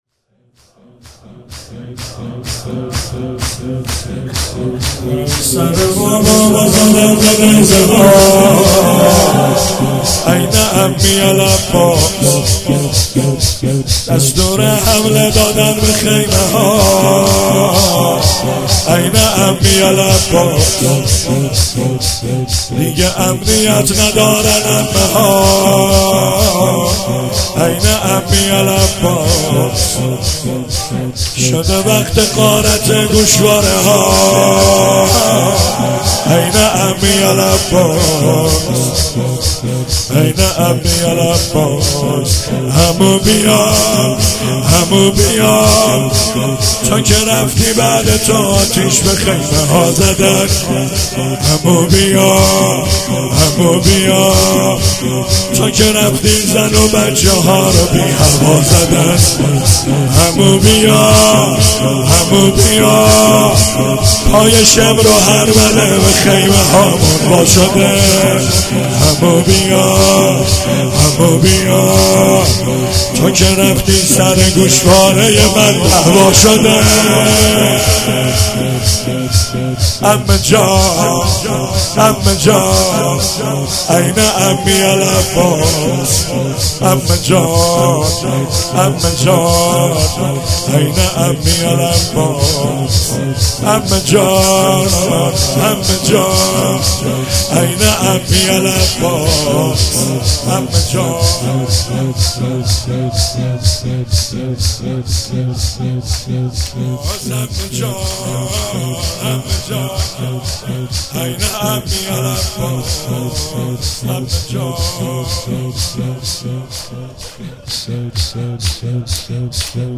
شب شام غریبان محرم 96 - شور - سره بابام رو زدن به نیزه ها